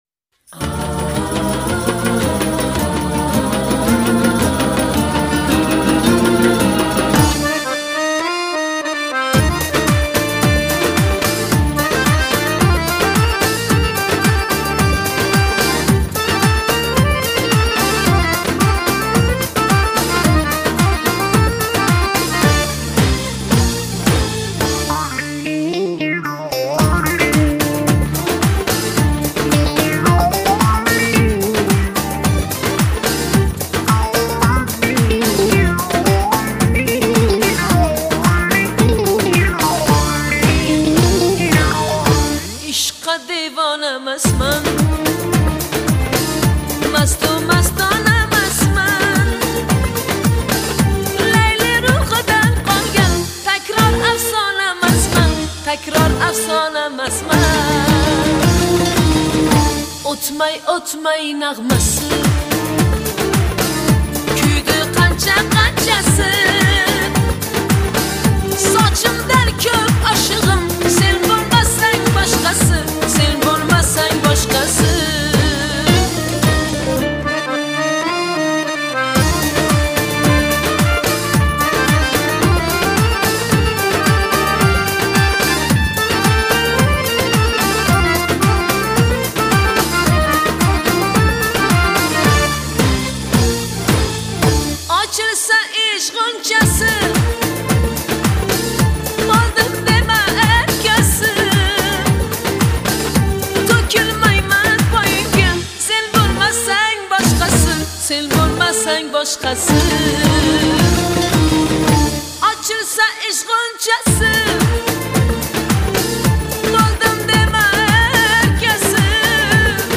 это яркая и мелодичная песня в жанре узбекской поп-музыки